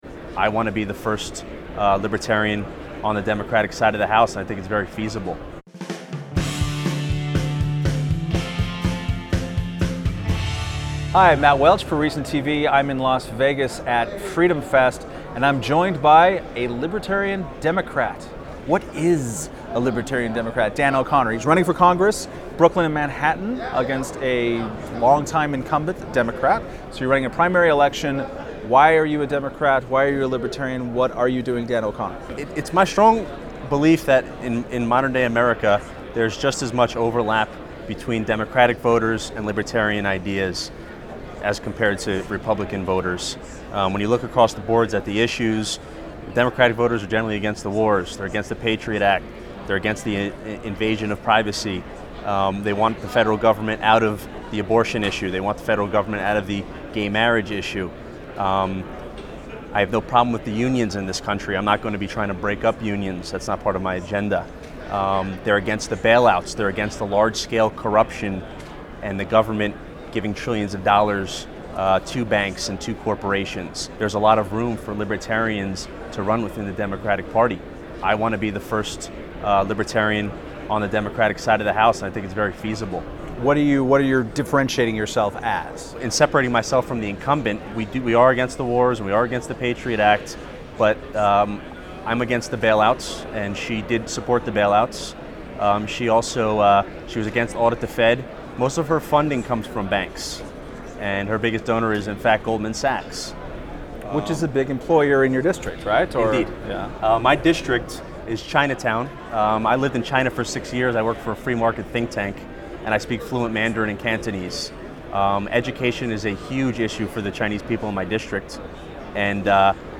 Held each July in Las Vegas, FreedomFest is attended by around 2,000 limited-government enthusiasts and libertarians a year.